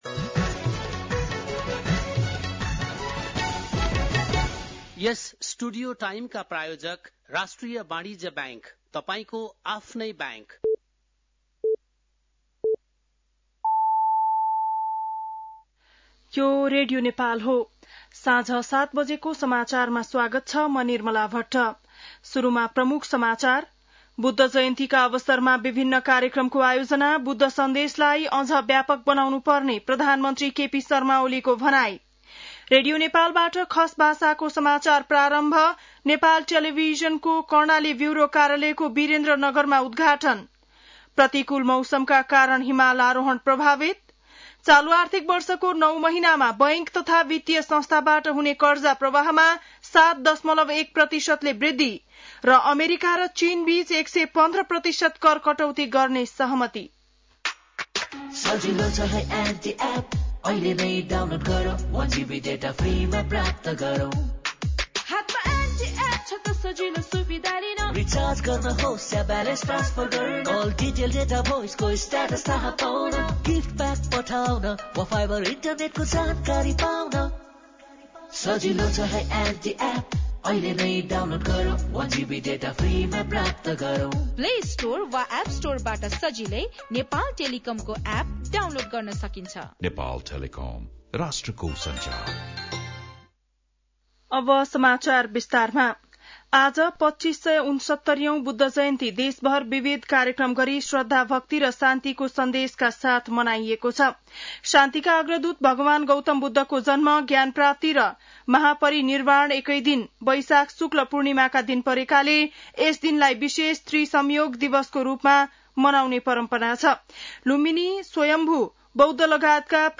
बेलुकी ७ बजेको नेपाली समाचार : २९ वैशाख , २०८२
7-pm-nepali-news.mp3